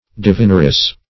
Divineress \Di*vin"er*ess\, n.